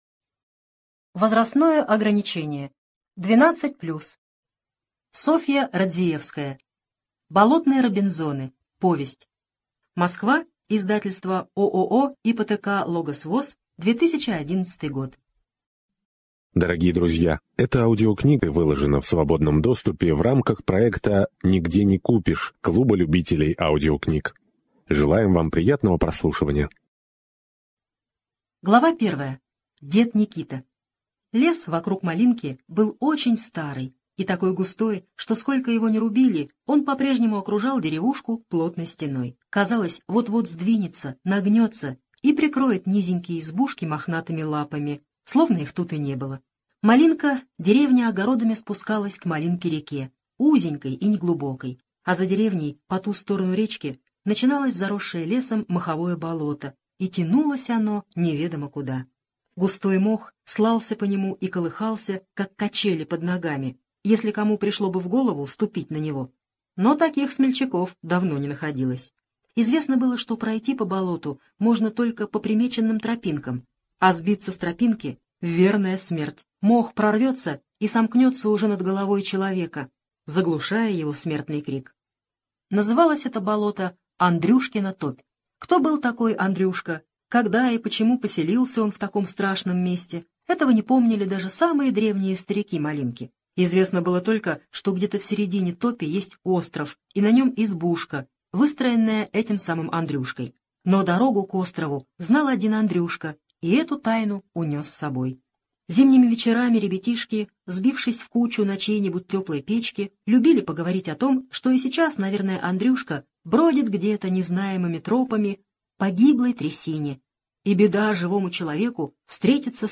На данной странице вы можете слушать онлайн бесплатно и скачать аудиокнигу "Болотные робинзоны" писателя Софья Радзиевская.